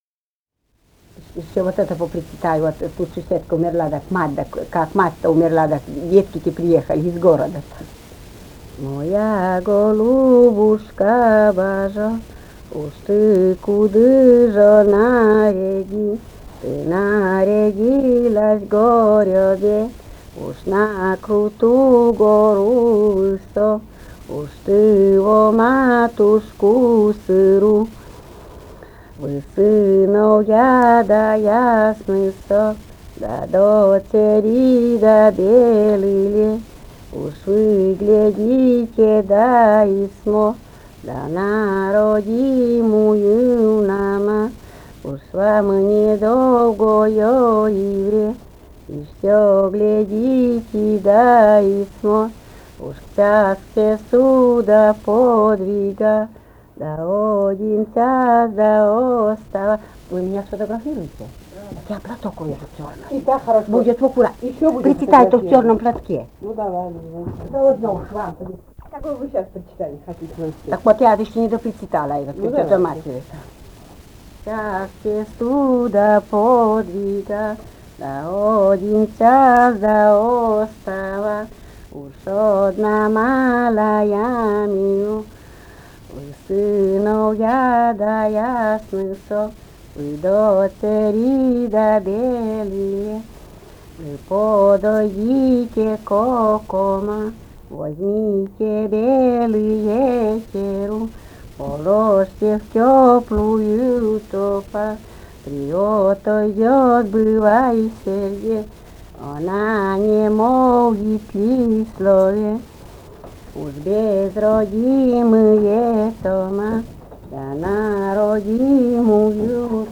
«Моя голубушка» (причитание матери).